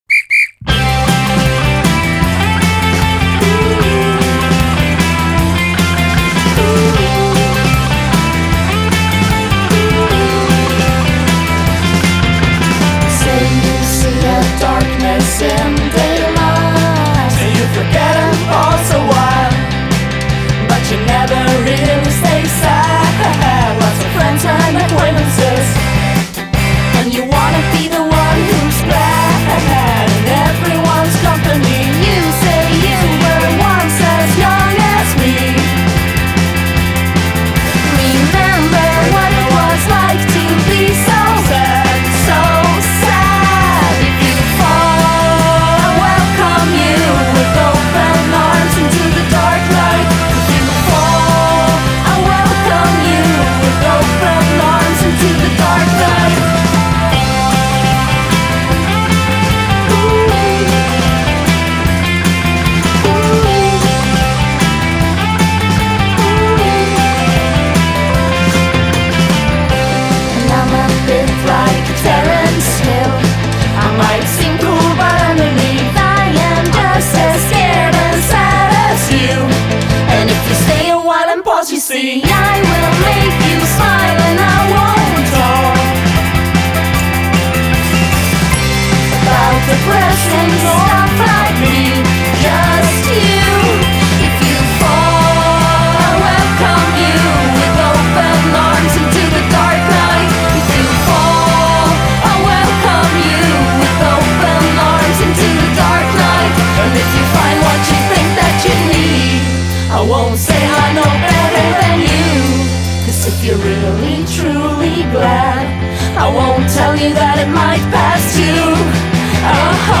sestetto di Stoccolma